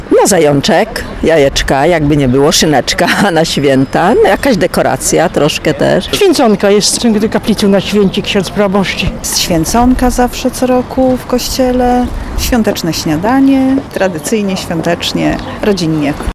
2014-mieszkancy-swieconka.mp3